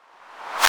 808CP_Z_Rev.wav